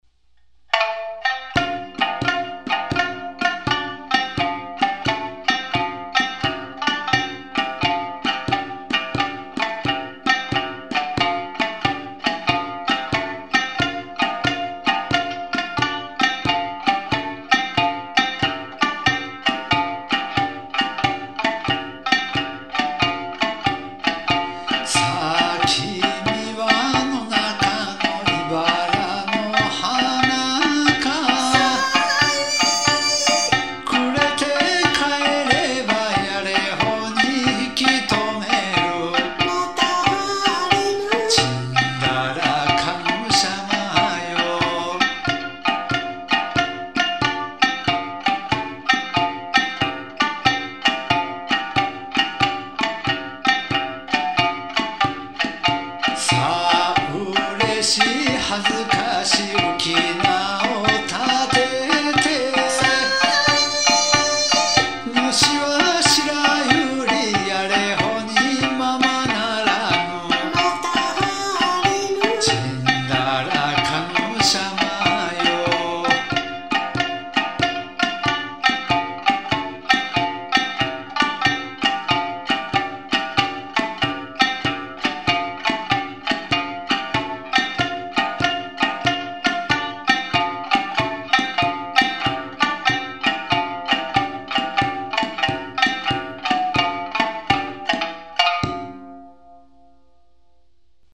（早弾き）
歌三線
三線　笛　太鼓　三板